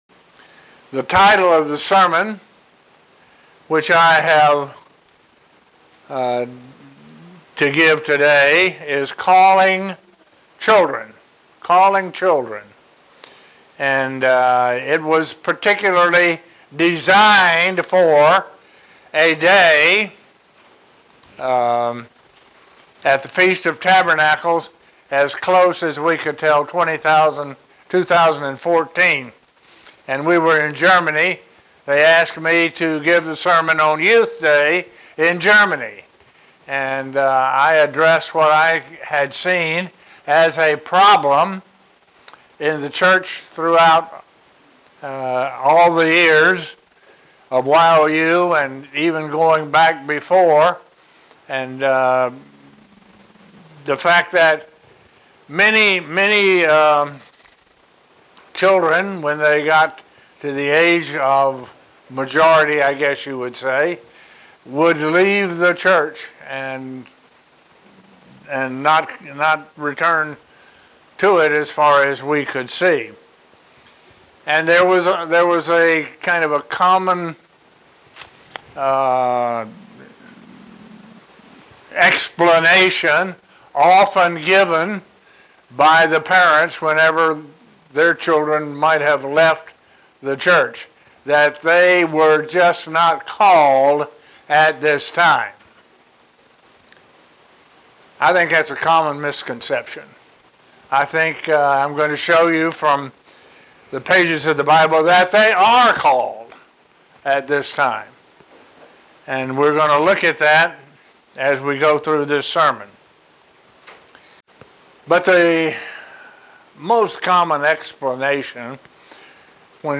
Is God calling children? This will be addressed in this sermon.